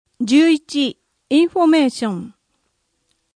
声の「広報はりま」8月号
声の「広報はりま」はボランティアグループ「のぎく」のご協力により作成されています。